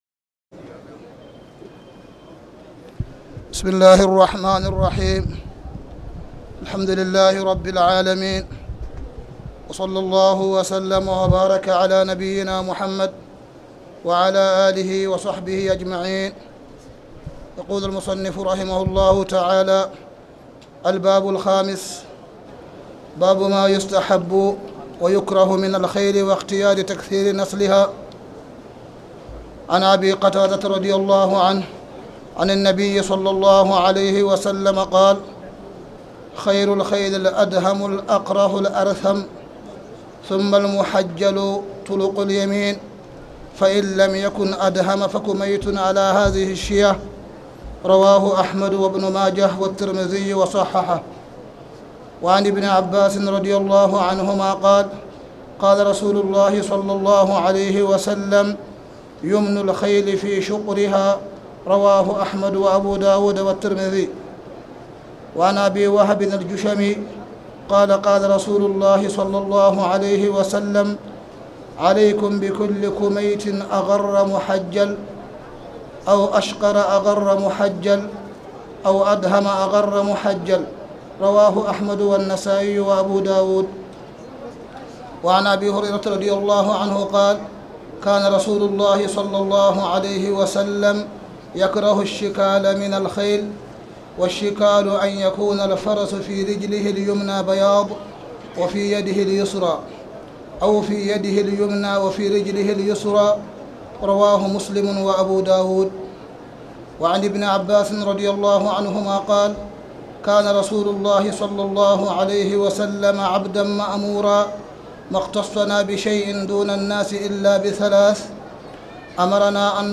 تاريخ النشر ١٠ رمضان ١٤٣٨ هـ المكان: المسجد الحرام الشيخ: معالي الشيخ أ.د. صالح بن عبدالله بن حميد معالي الشيخ أ.د. صالح بن عبدالله بن حميد باب مايستحب ويكره من الخيل